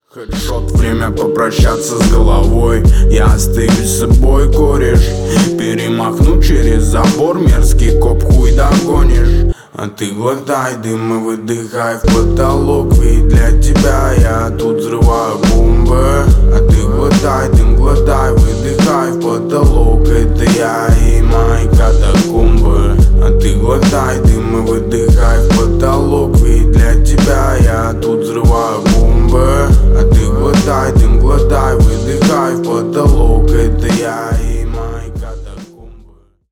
Рэп и Хип Хоп
спокойные